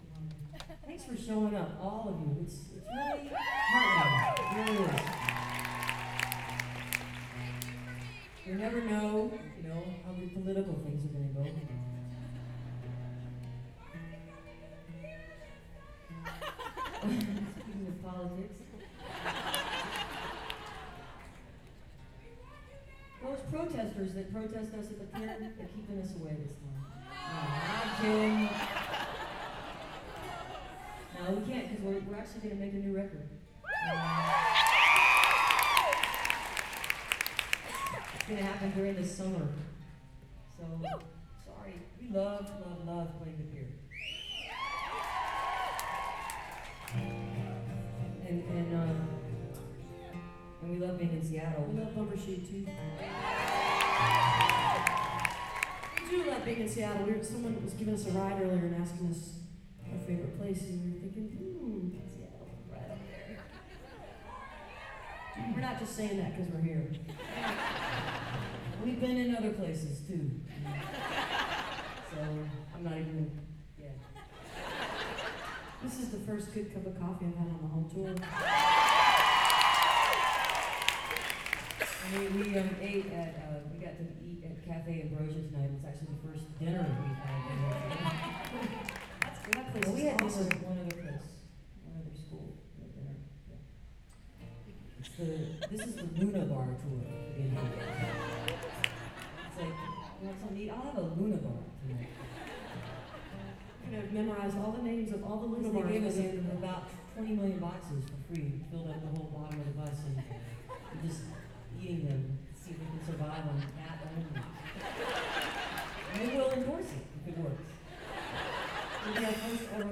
lifeblood: bootlegs: 2003-04-15: hub ballroom (university of washington) - seattle, washington (honor the earth benefit with winona laduke)
14. talking with the crowd (2:11)